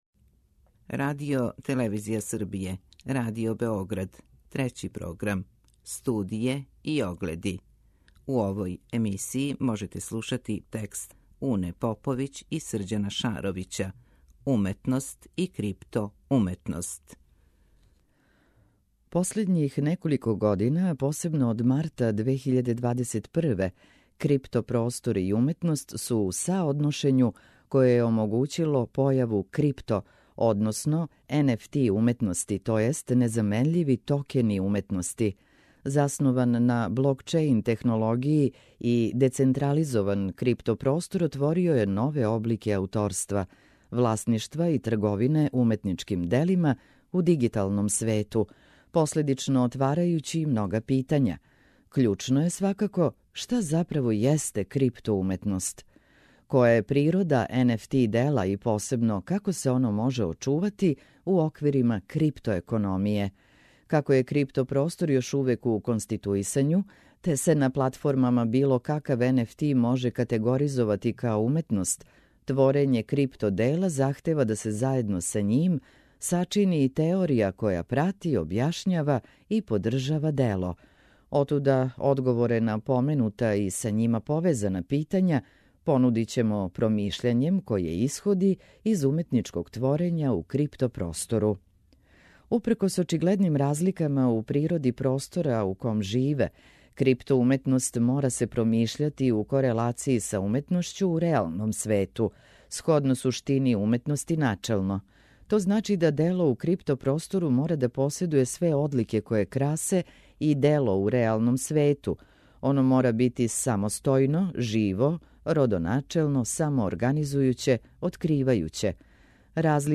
Прва говорна емисија сваке вечери од понедељка до петка.